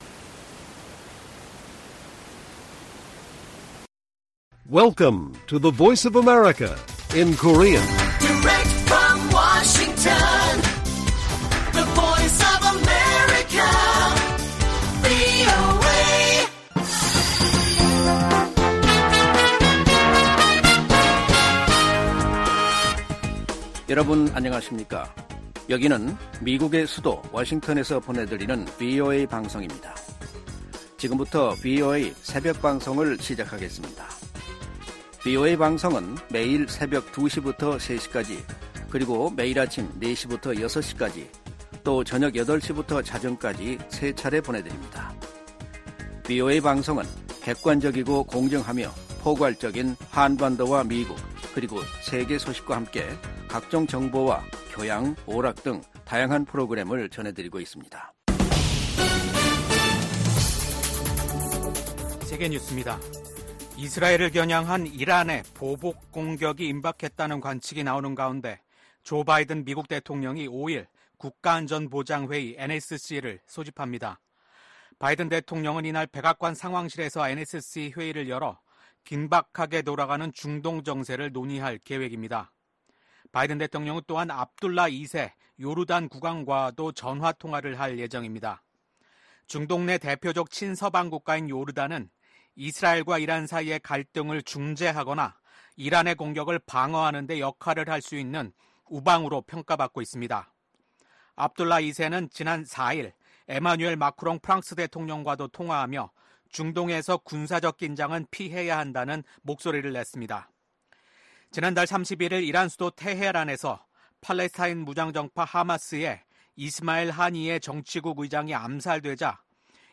VOA 한국어 '출발 뉴스 쇼', 2024년 8월 6일 방송입니다. 북한이 핵탄두 장착이 가능한 신형 전술탄도미사일 발사대를 대규모 전방 배치한다고 발표했습니다.